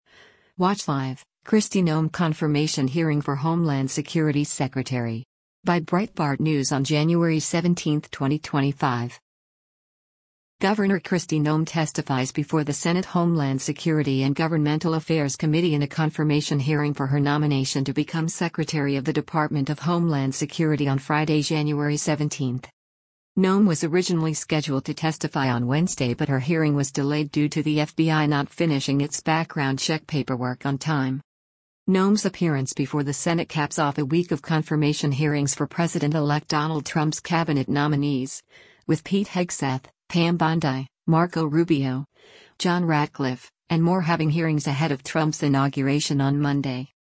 Gov. Kristi Noem testifies before the Senate Homeland Security and Governmental Affairs Committee in a confirmation hearing for her nomination to become Secretary of the Department of Homeland Security on Friday, January 17.